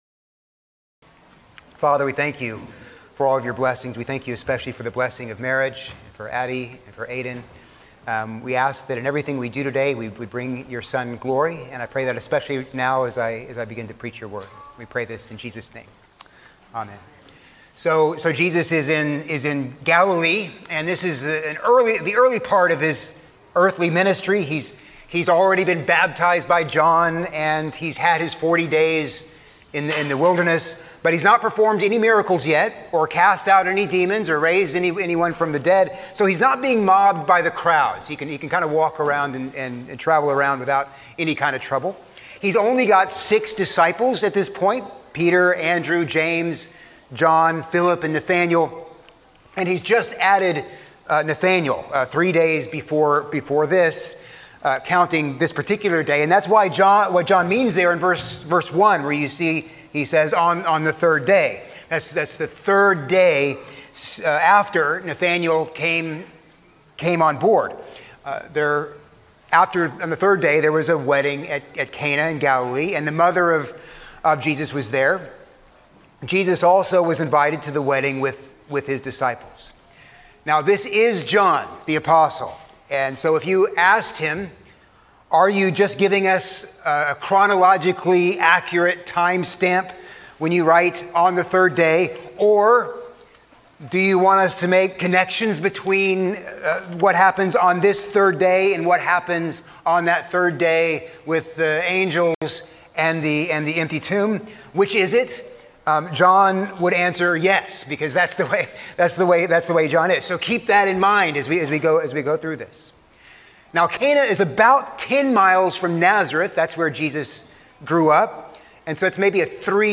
A sermon on John 2:1-11